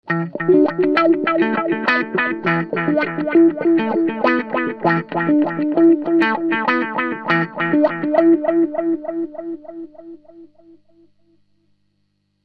Аналоговый дилэй Electro-Harmonix Deluxe Memory Man
Crybaby Riff (145 кБ)
crybaby_riff.mp3